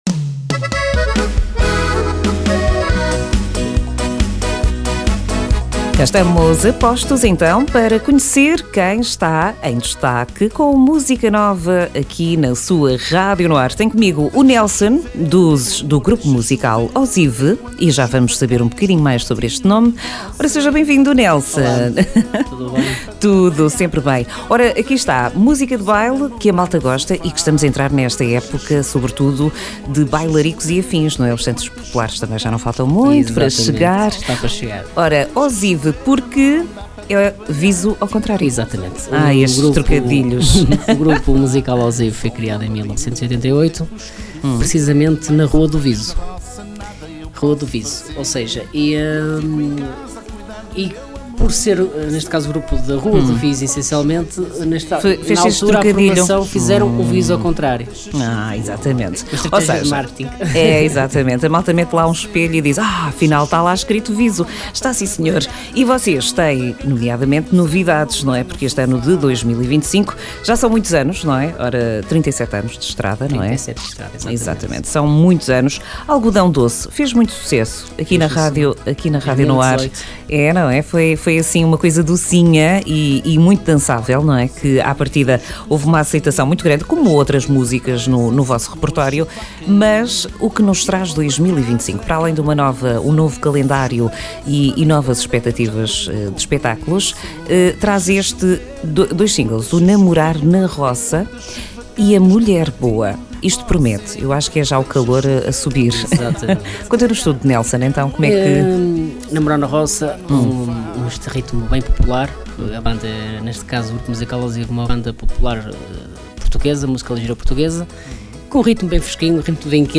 Entrevista Grupo Musical Osiv dia 23 de Maio 2025.
ENTREVISTA-OSIV-MAI-2025.mp3